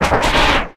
Audio / SE / Cries / TREECKO.ogg